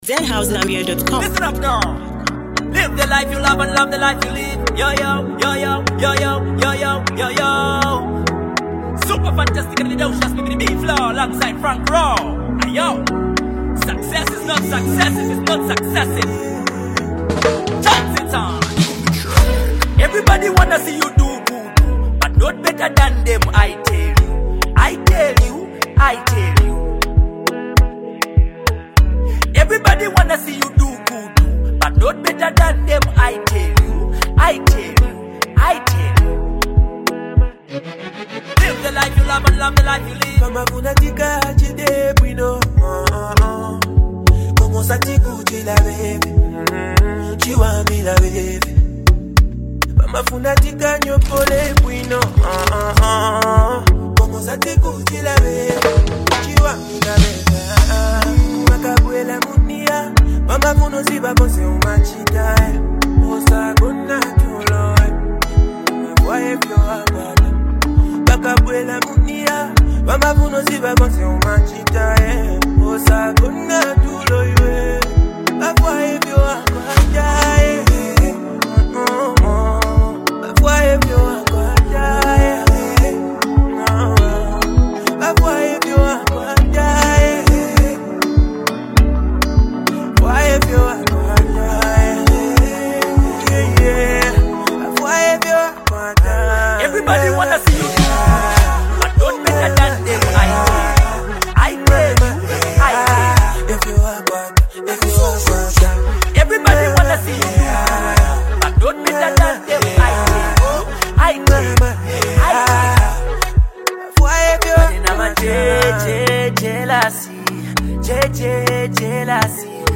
sweet, soulful tune